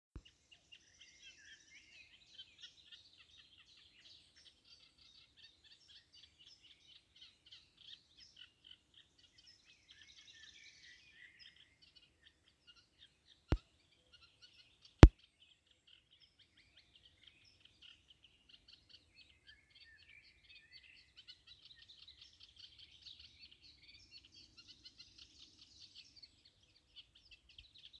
тростниковая камышевка, Acrocephalus scirpaceus
Administratīvā teritorijaGarkalnes novads
СтатусПоёт